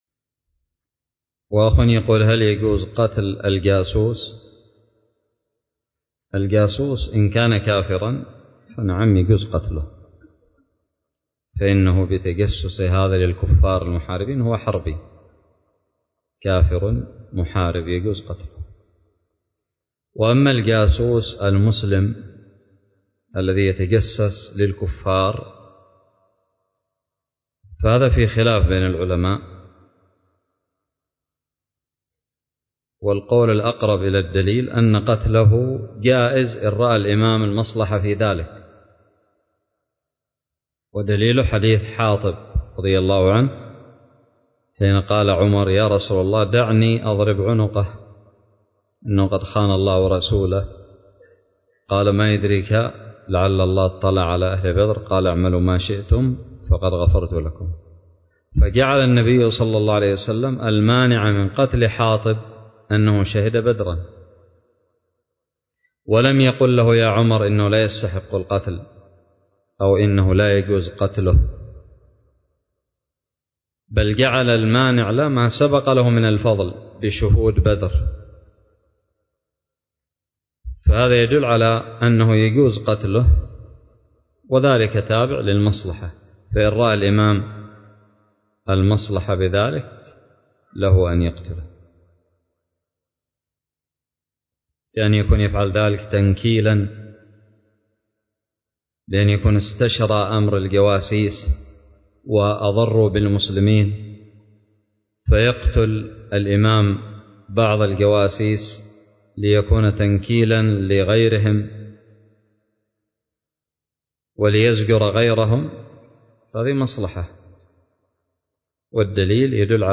:العنوان فتاوى عامة :التصنيف 1447-4-2 :تاريخ النشر 55 :عدد الزيارات البحث المؤلفات المقالات الفوائد الصوتيات الفتاوى الدروس الرئيسية هل يجوز قتل الجاسوس؟ سؤال قدم لفضيلة الشيخ حفظه الله